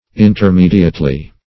\In`ter*me"di*ate*ly\